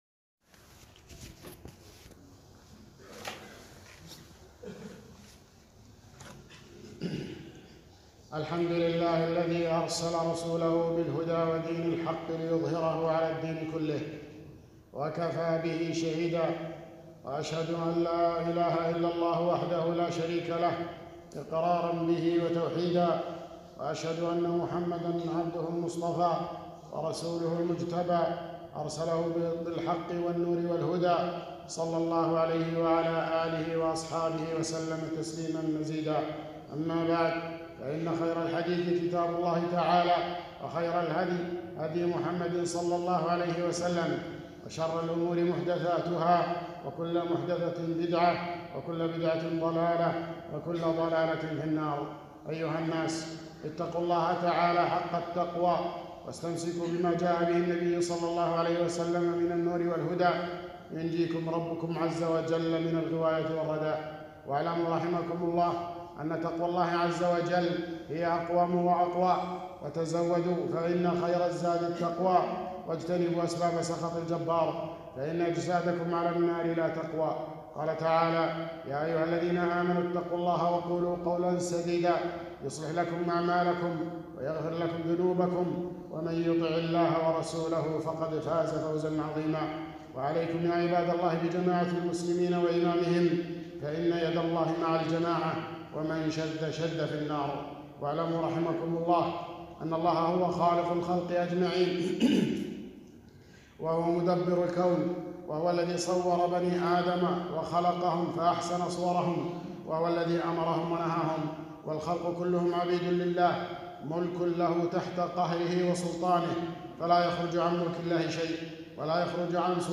خطبة - اغاليط الحرية